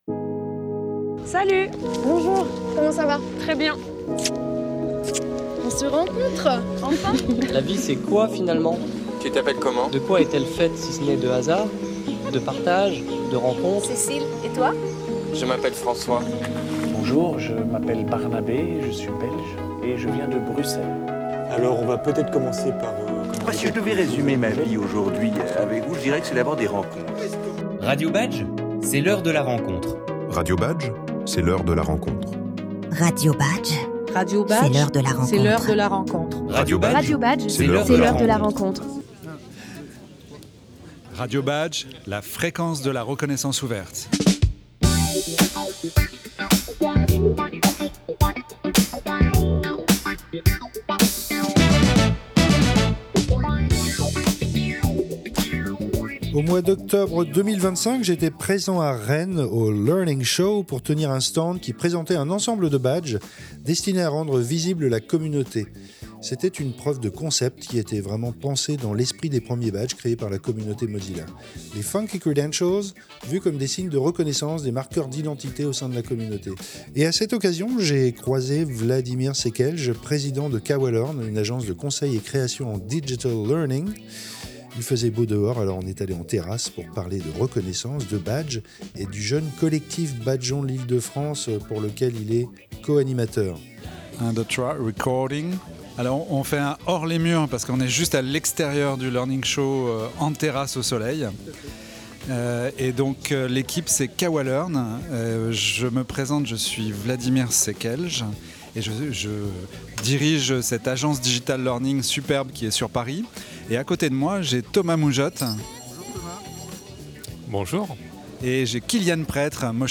Il faisait beau dehors, alors on est allés en terrasse pour parler de reconnaissance, de badges, et du jeune collectif Badgeons l’Ile de France.